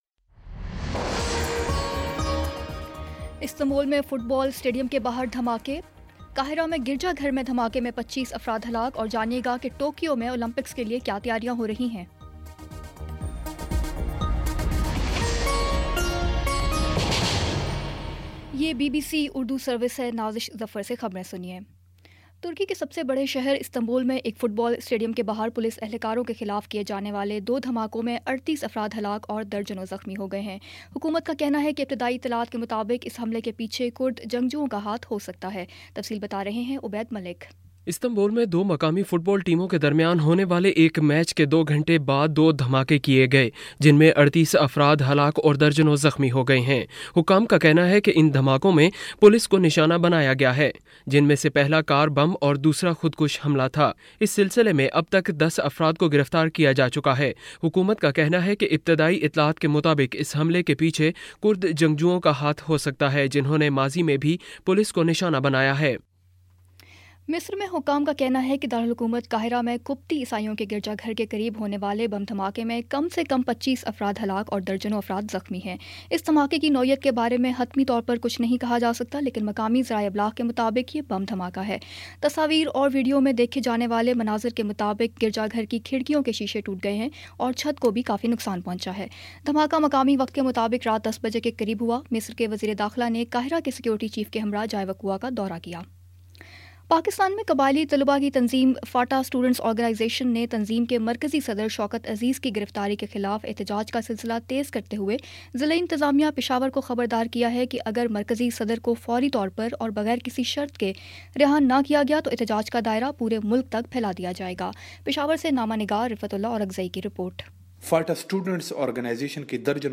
دسمبر 11 : شام چھ بجے کا نیوز بُلیٹن